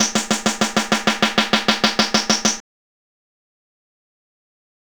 Rock Star - Snare Roll.wav